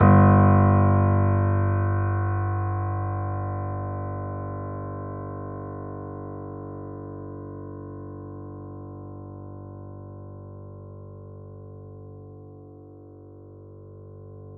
piano
G1.wav